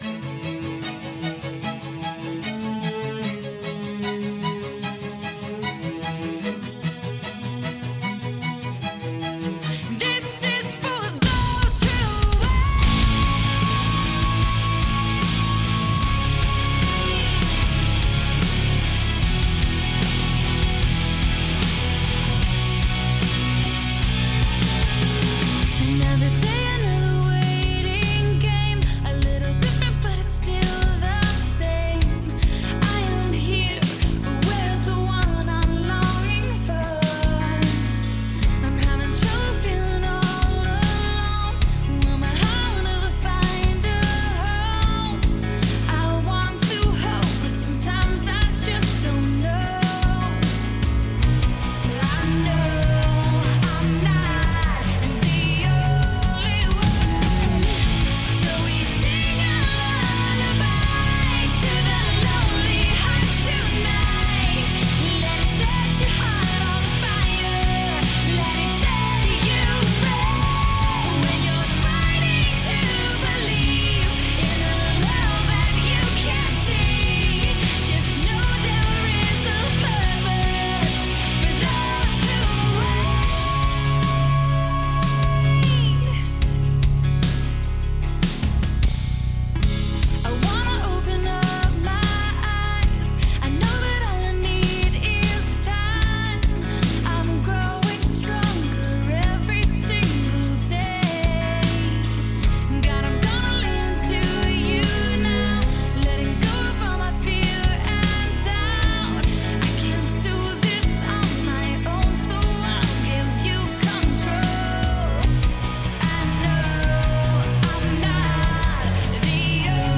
Жанр: Alternative / Rock